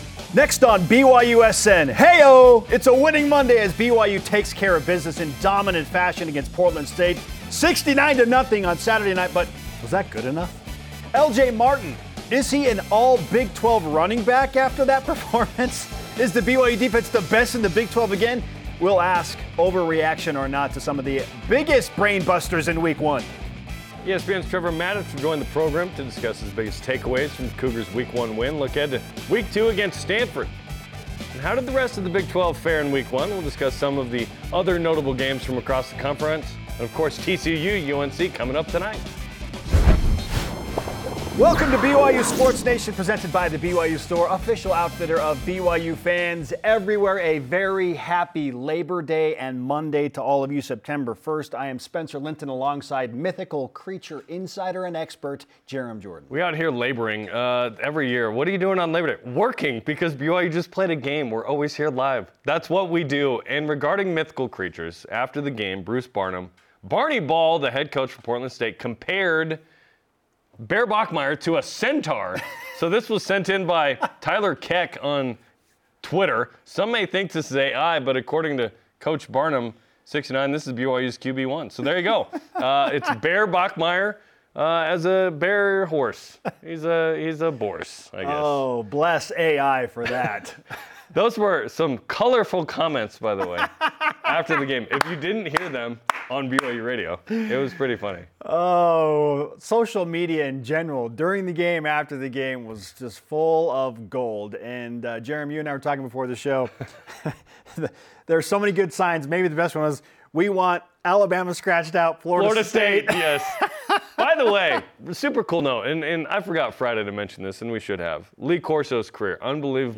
Live from Studio B